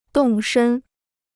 动身 (dòng shēn): to go on a journey; to leave.